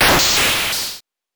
SFX
8 bits Elements